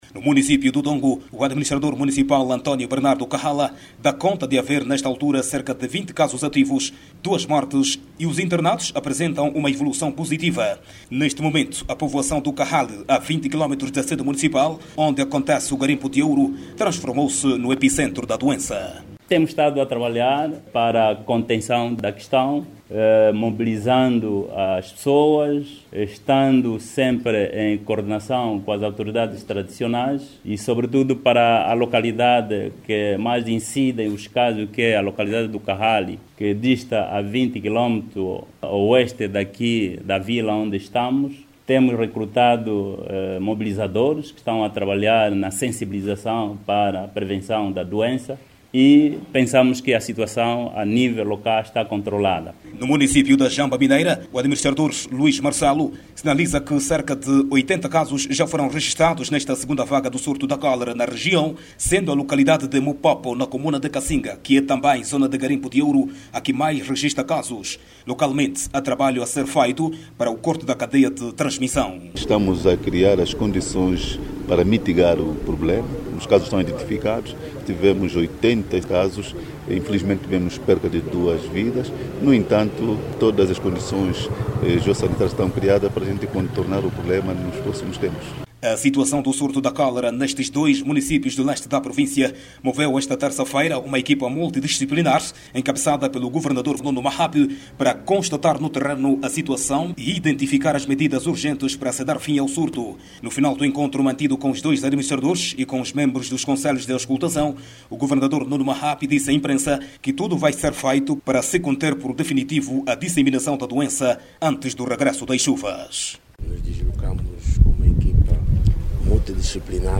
NOTÍCIAS